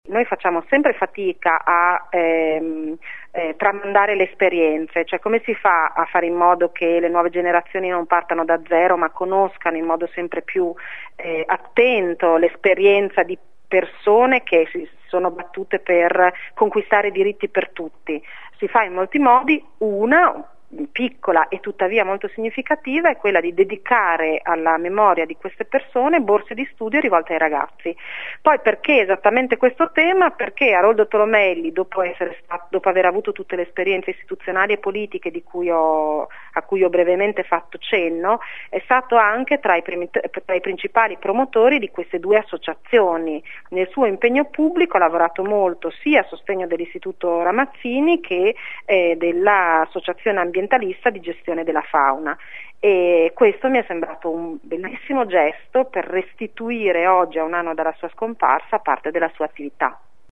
Alla presentazione della borsa di studio era presente la presidente del consiglio comunale Simona Lembi ascolta